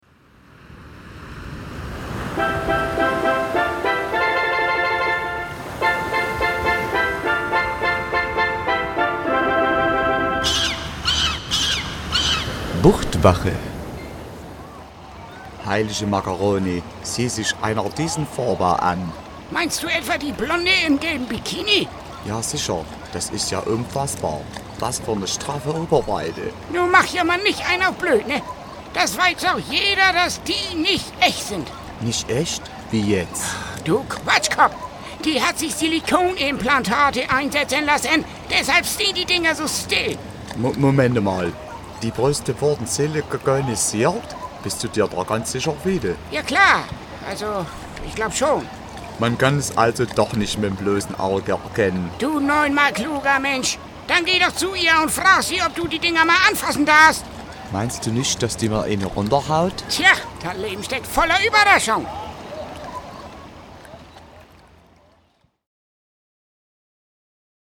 BUCHTWACHE, Radiocomedy
Kurzinhalt: Ein alter und ein sächsischer Rettungsschwimmer